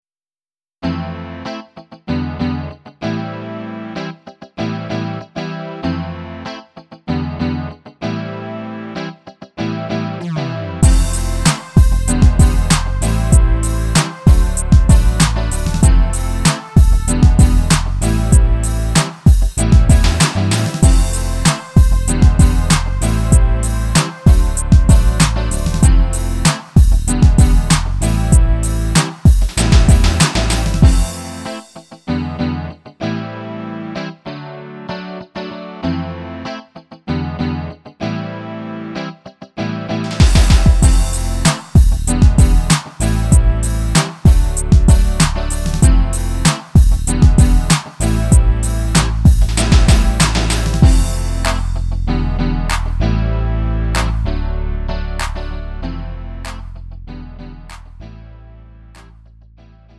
음정 -1키 3:17
장르 구분 Lite MR